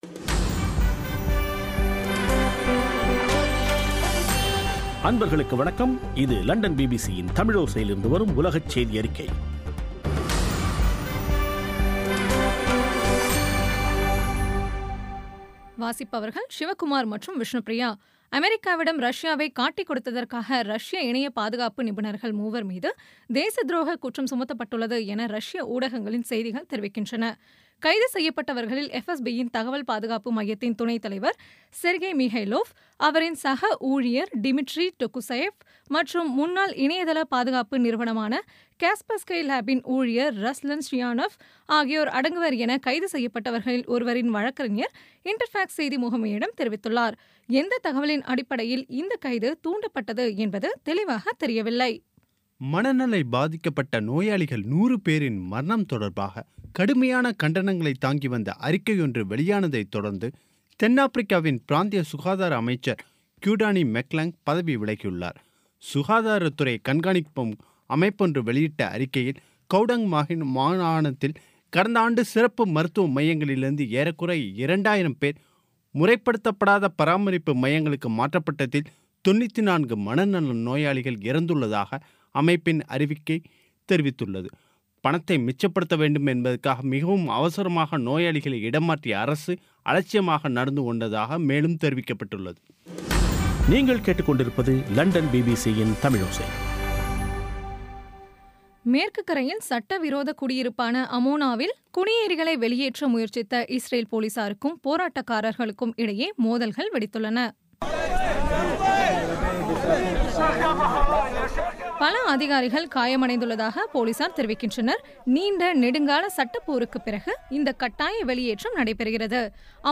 பிபிசி தமிழோசை செய்தியறிக்கை (01.02.17)